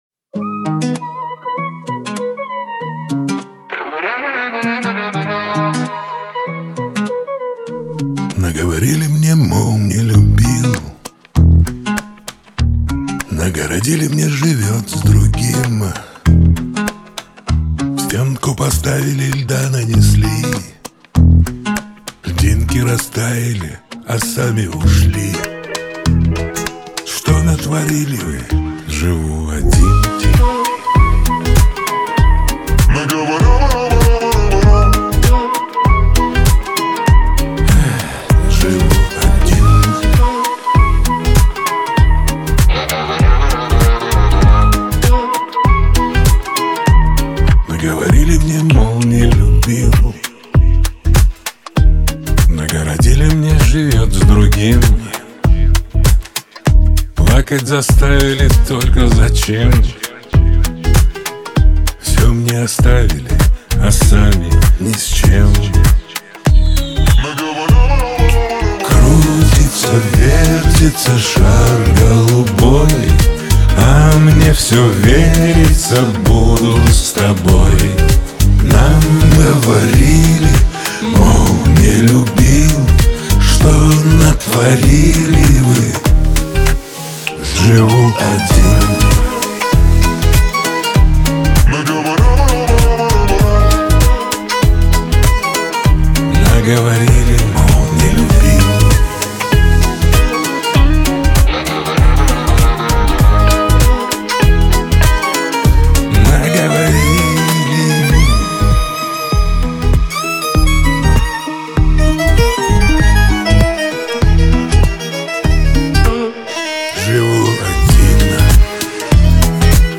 Главная ➣ Жанры ➣ Поп музыка. 2025.